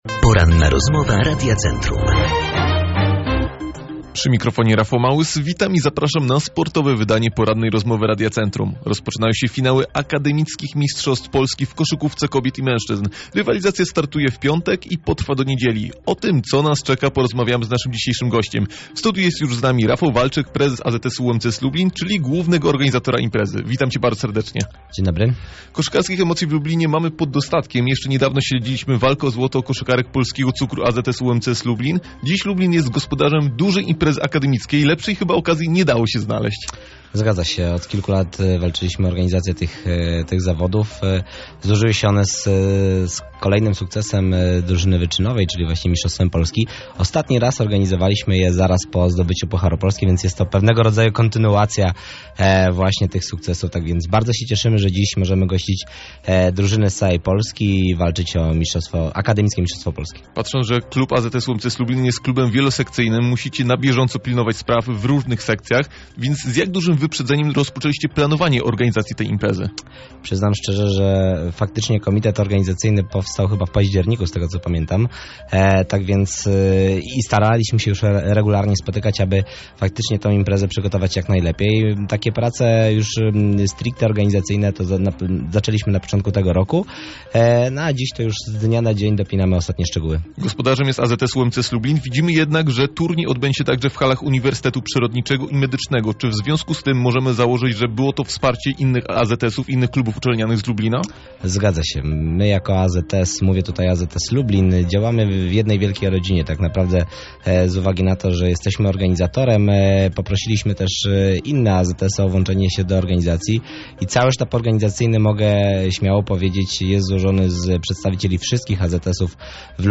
Więcej o mistrzostwach mogliście usłyszeć w dzisiejszej Porannej Rozmowie Radia Centrum.